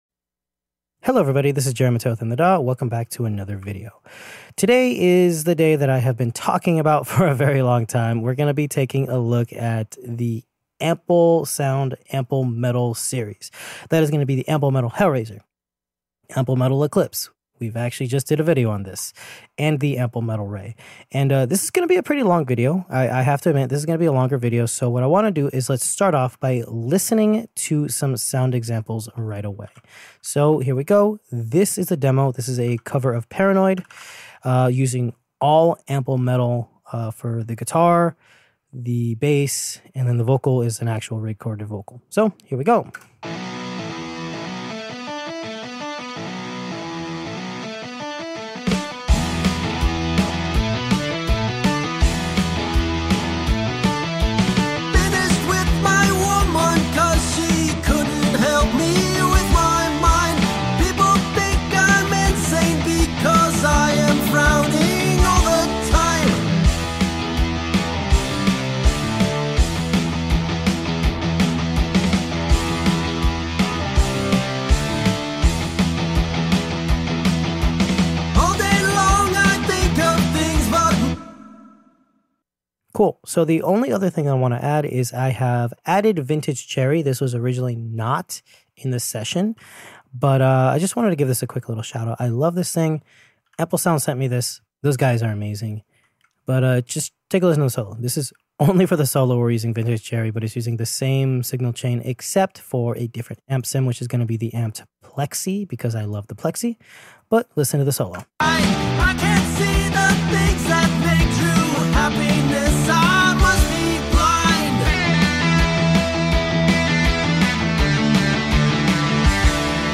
FULL 17 mins 😲 Review and Overview of our Ample Sound Metal series which has 3 guitars for Metal genre.